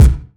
• Clean Steel Kick Drum C Key 588.wav
Royality free kick sound tuned to the C note. Loudest frequency: 432Hz
clean-steel-kick-drum-c-key-588-0Cb.wav